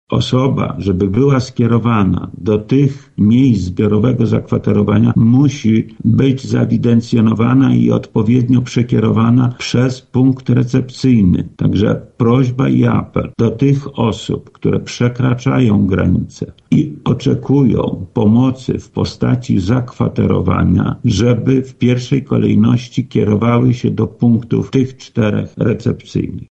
Wojewoda apeluje do obywateli Ukrainy, którzy potrzebują zakwaterowania: